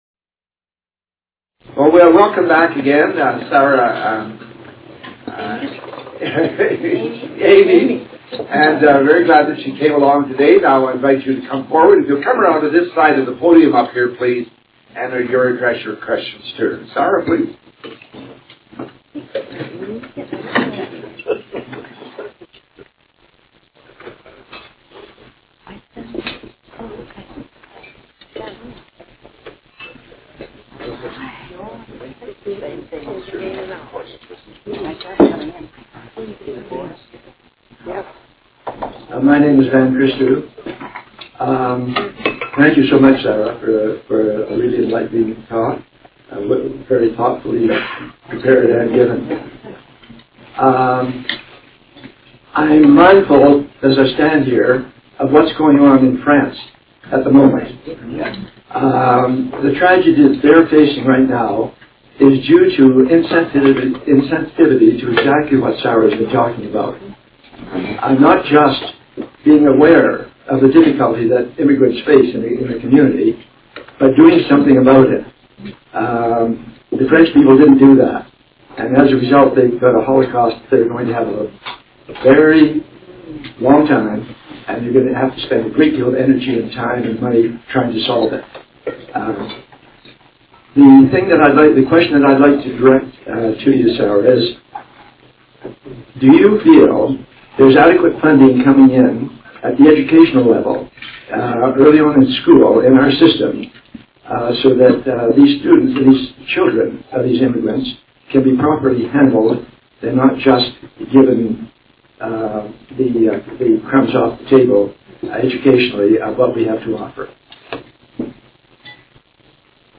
Location: Sven Ericksen''s Family Restaurant, 1715 Mayor Magrath Drive S.